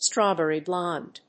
アクセントstráwberry blónde